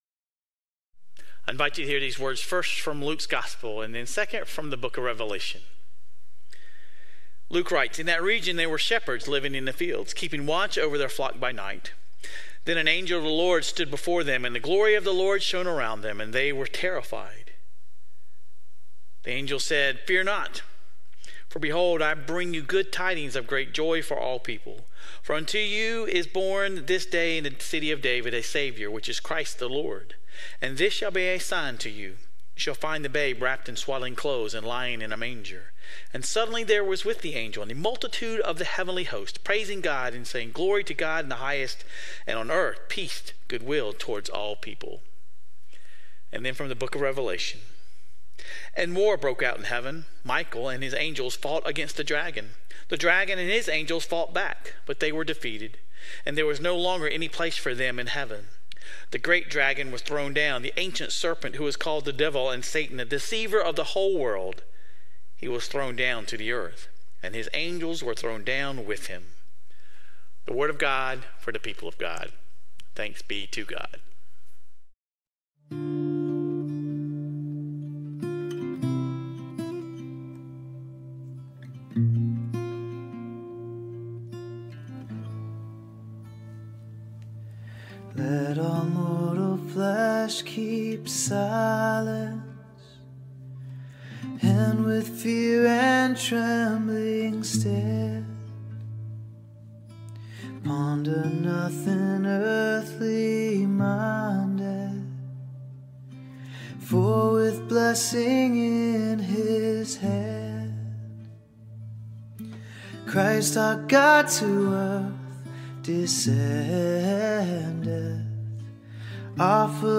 This week, we explore the "Heavenly Hosts." Sermon Reflections: How does the image of God as the Lord of Hosts impact your understanding of God's power and sovereignty?